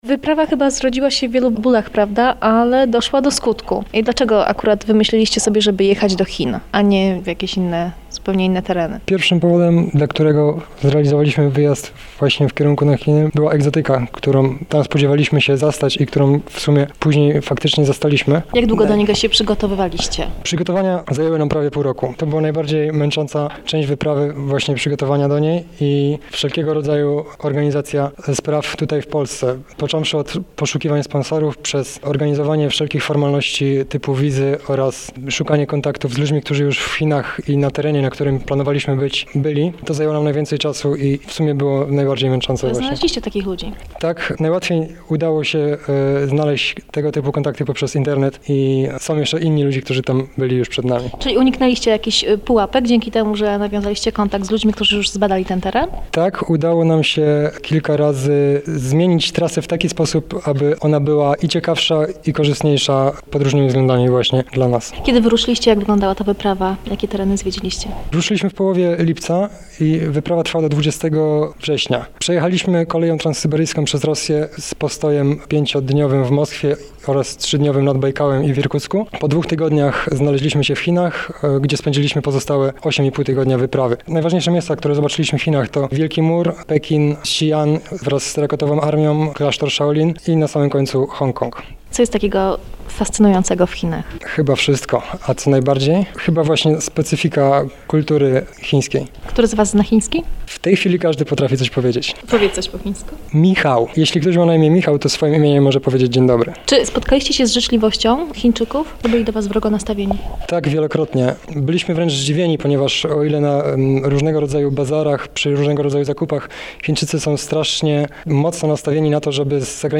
Wyemitowany na antenie w dniu 28 grudnia 2001.